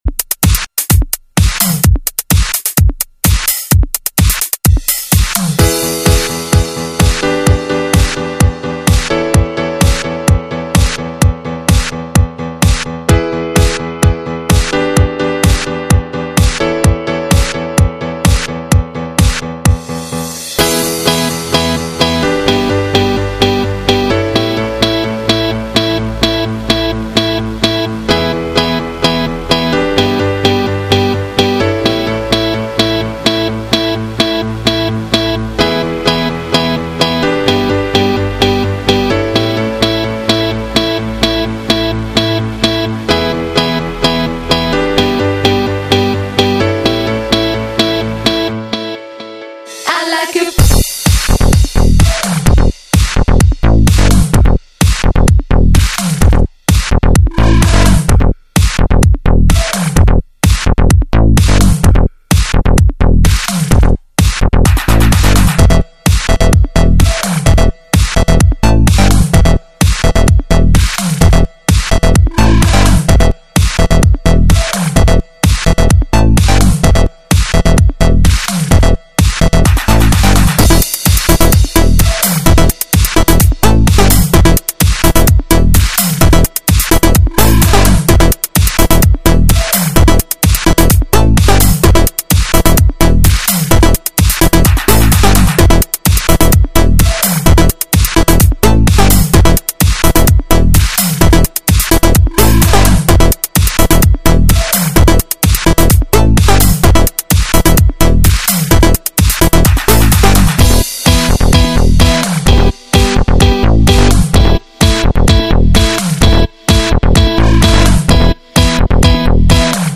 Жанр:Новогодний/Позитивный/Electro/House